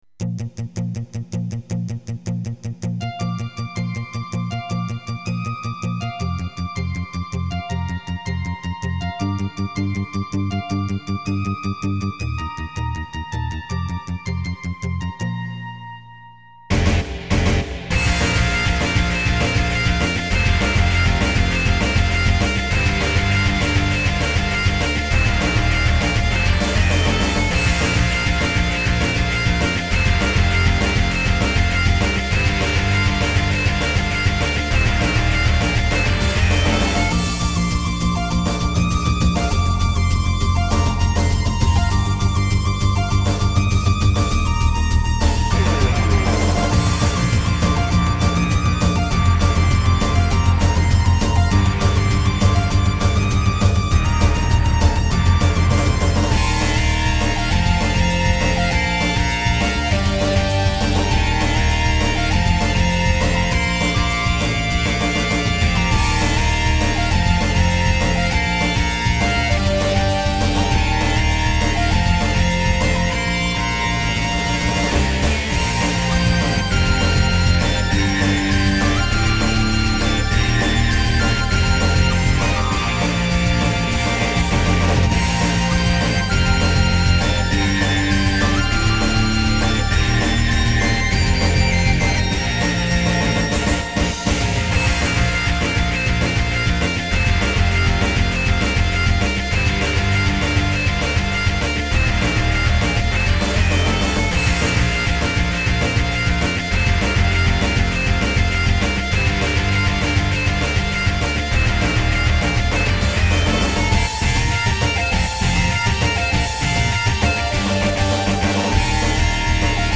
激しく勢いのあるミュージックを中心に、個性的な素材ばかりです。